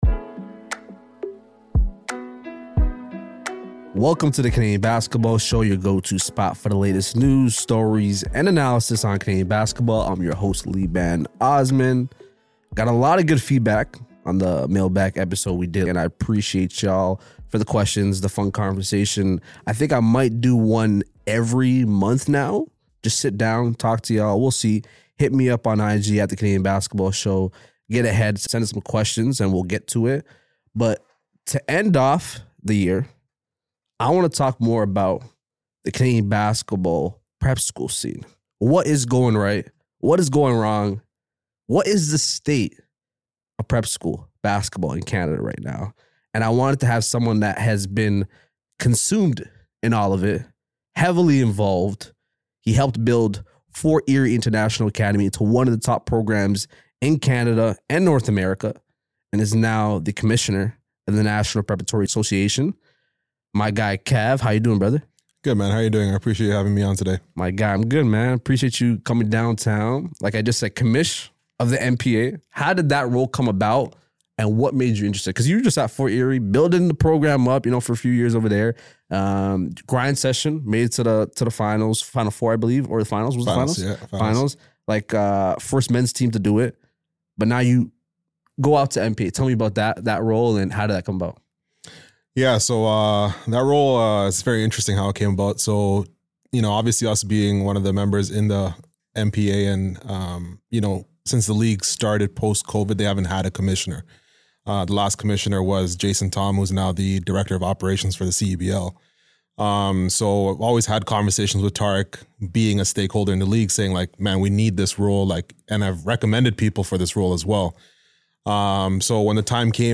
Your go-to spot for the latest news, stories and analysis on Canadian basketball. Tune in every Tuesday for exclusive interviews and behind the scenes coverage on basketball in Canada.